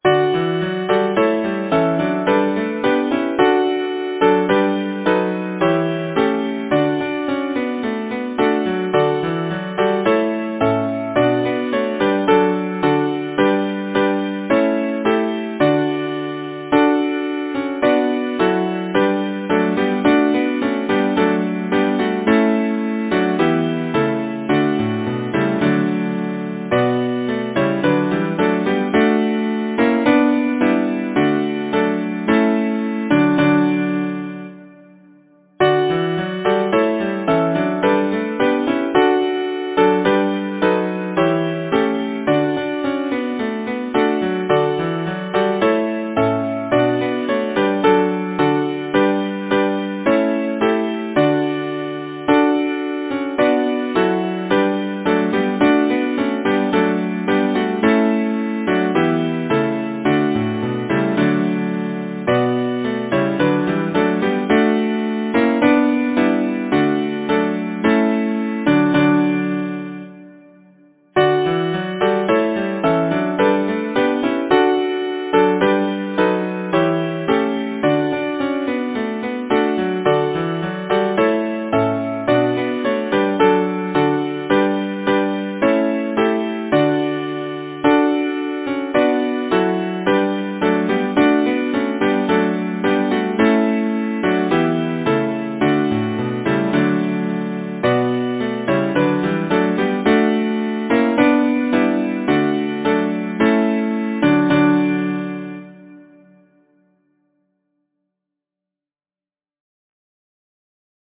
Composer: John Cornwall Lyricist: May Byron Number of voices: 4vv Voicing: SATB Genre: Secular, Partsong
Language: English Instruments: A cappella